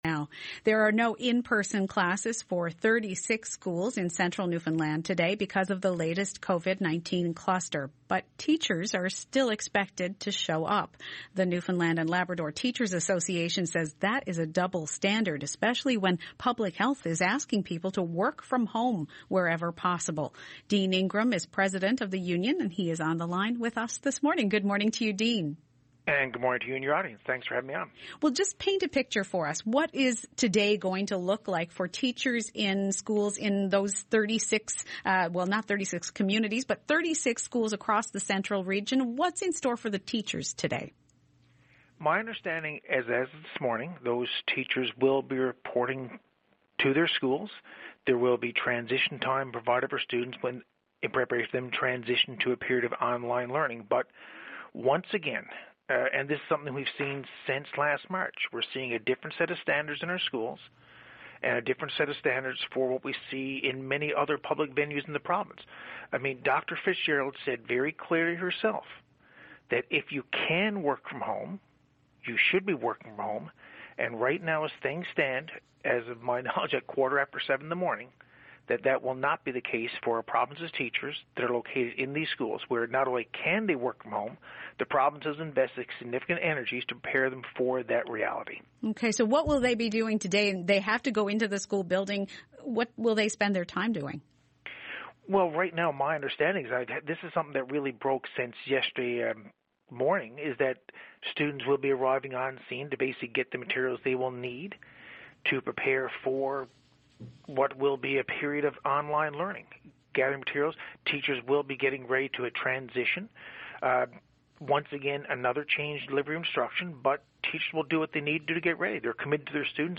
Media Interview - CBC NL Morning - May 25, 2021